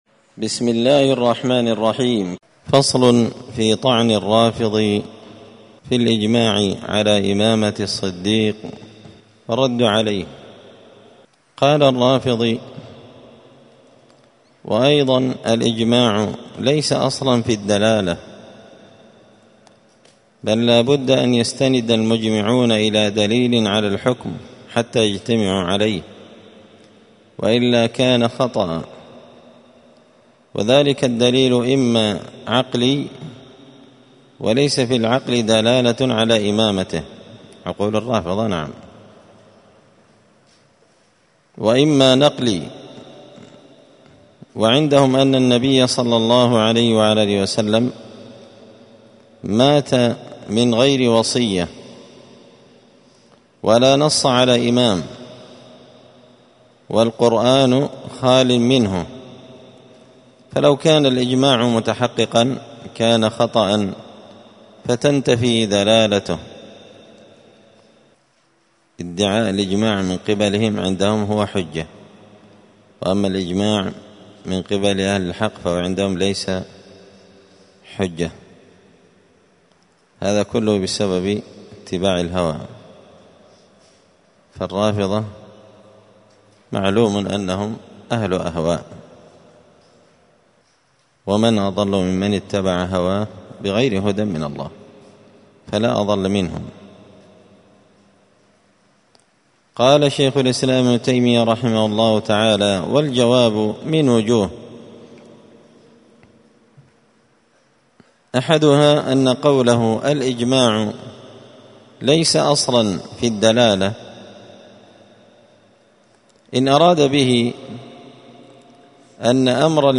*الدرس الثامن والثلاثون بعد المائتين (238) فصل في طعن الرافضي في الإجماع على إمامة الصديق والرد عليه*